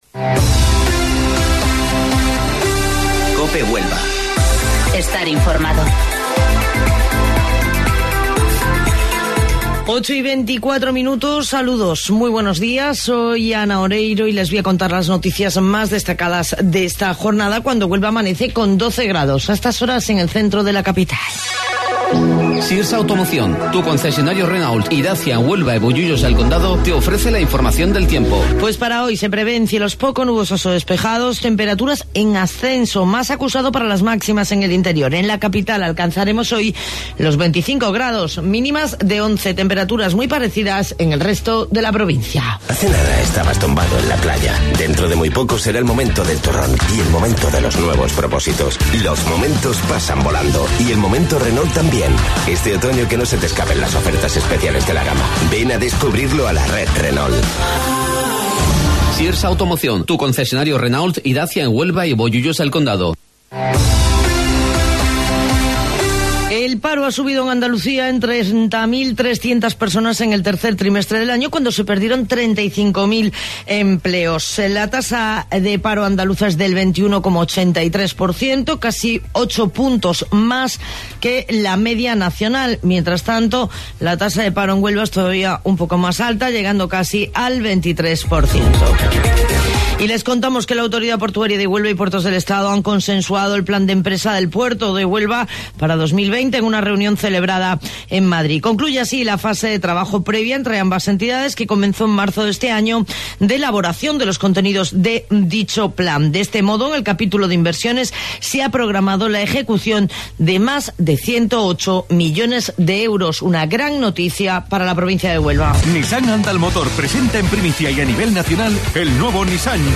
AUDIO: Informativo Local 08:25 del 25 de Octubre